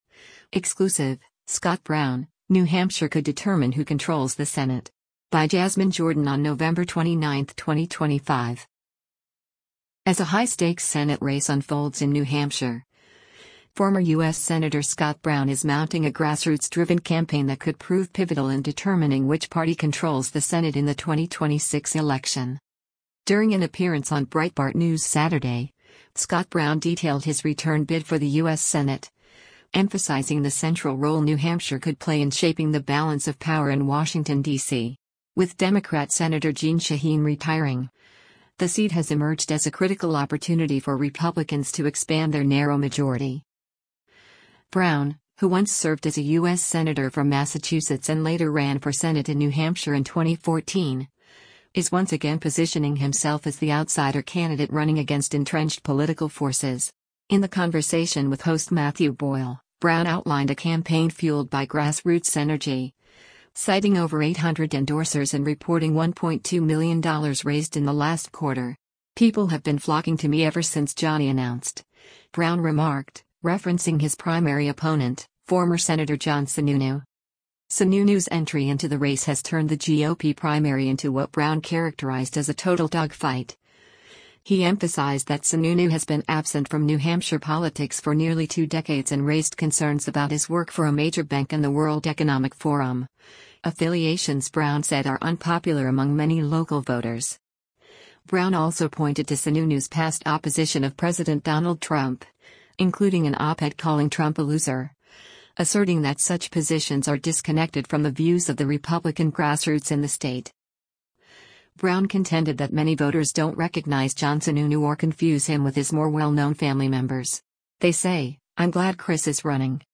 During an appearance on Breitbart News Saturday, Scott Brown detailed his return bid for the U.S. Senate, emphasizing the central role New Hampshire could play in shaping the balance of power in Washington, DC.